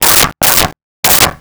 Dog Barking 07
Dog Barking 07.wav